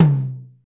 Index of /90_sSampleCDs/Northstar - Drumscapes Roland/DRM_R&B Groove/TOM_R&B Toms x
TOM R B H0HR.wav